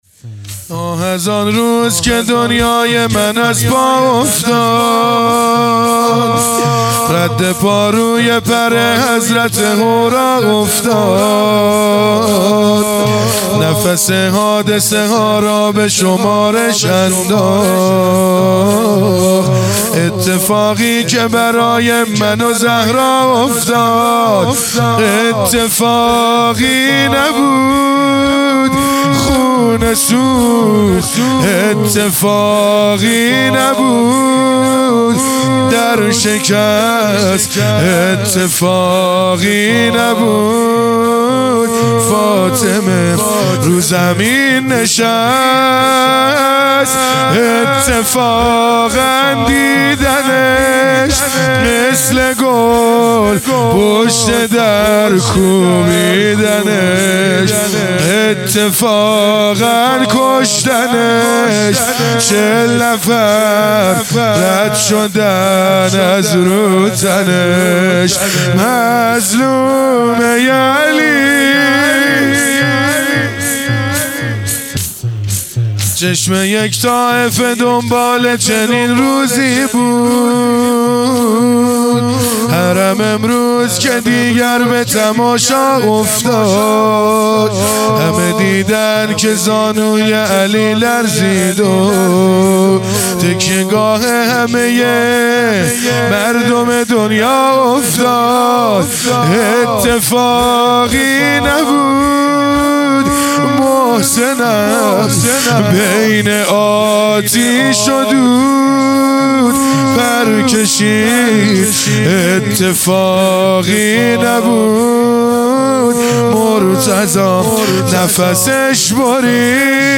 دهه دوم فاطمیه | شب اول | زمینه